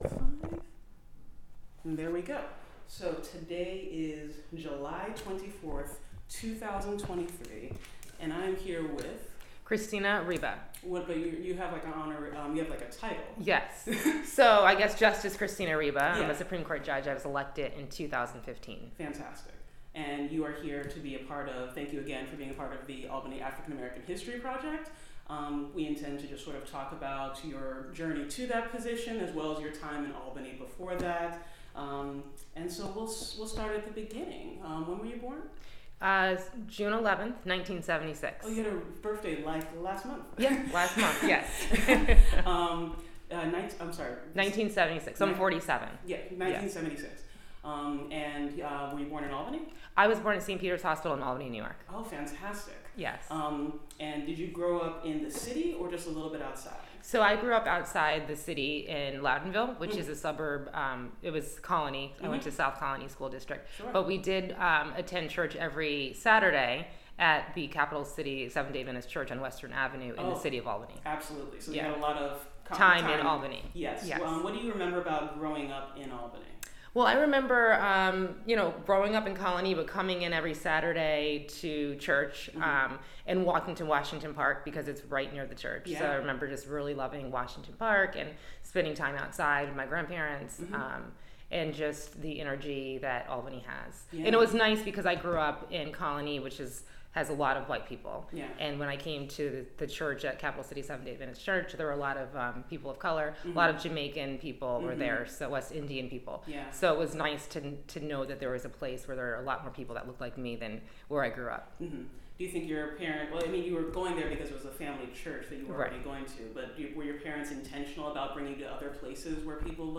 Oral Interview with Justice Christina Ryba
Justice-Christina-Ryba-interview.mp3